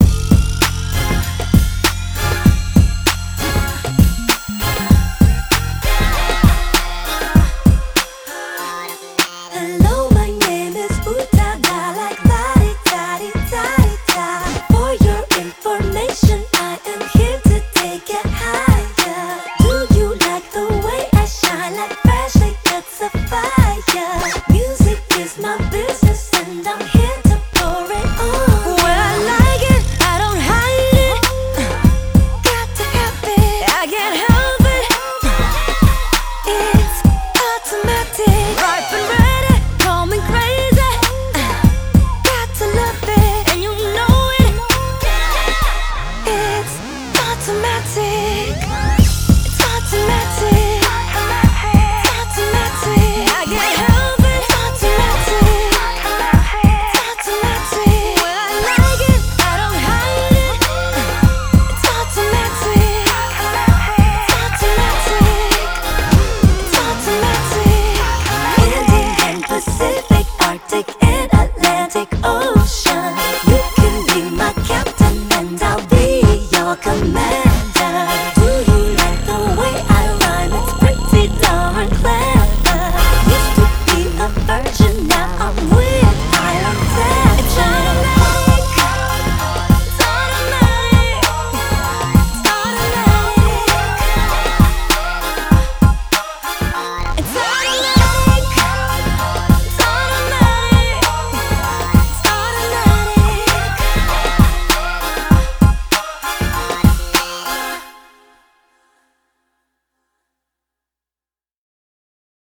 BPM98
R&B